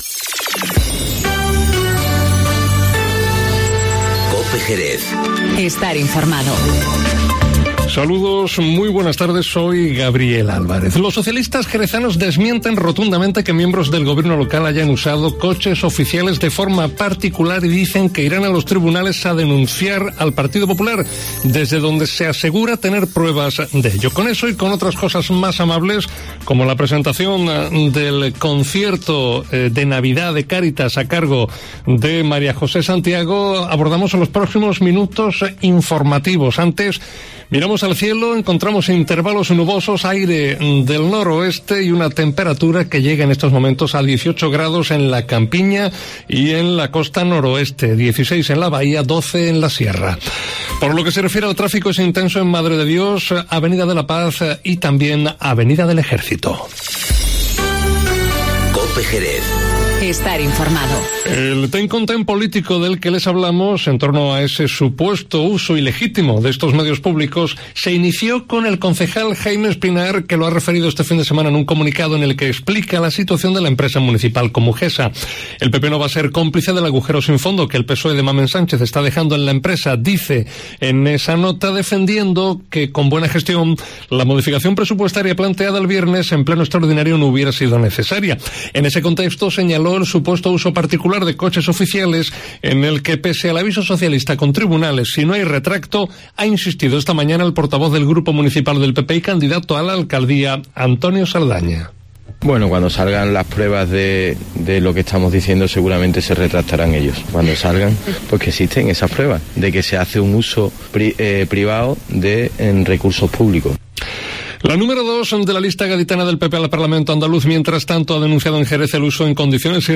Informativo Mediodía COPE Jerez